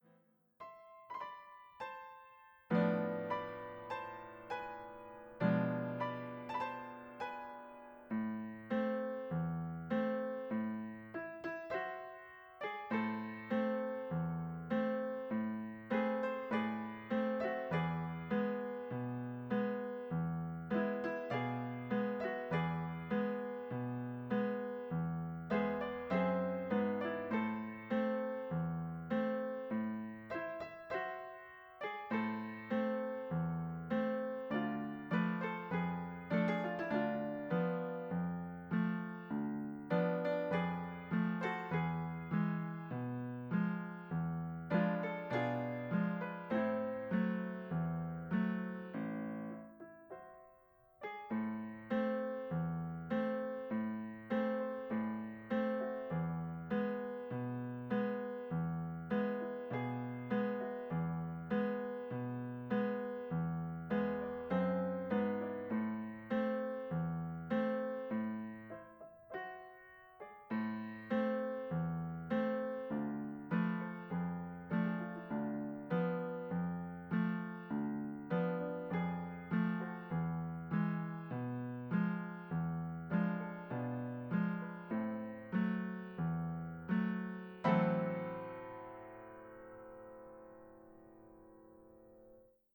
Folk song